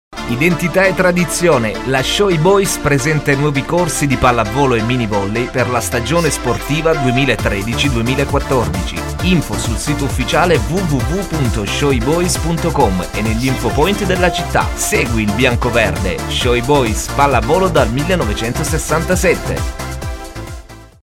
– Spot promozionale di Radio Orizzonti Activity per l’apertura delle iscrizioni ai corsi di minivolley e pallavolo: